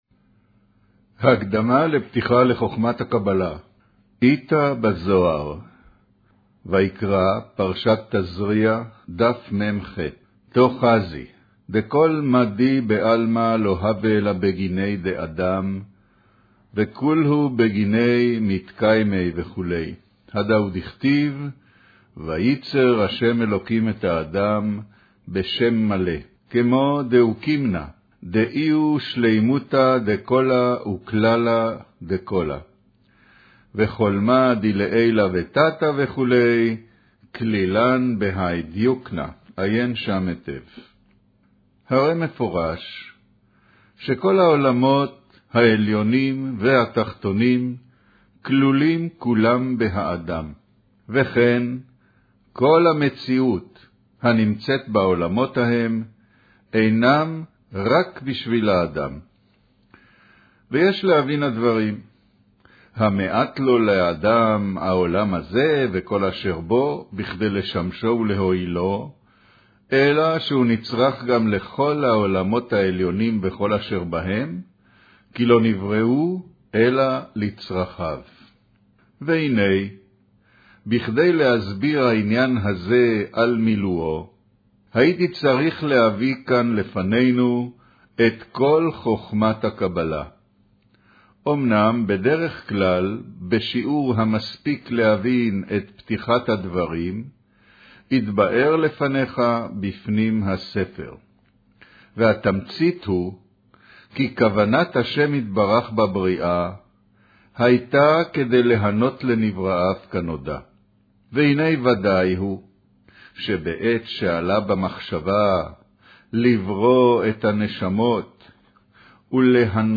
קריינות הקדמה לפתיחה לחכמת הקבלה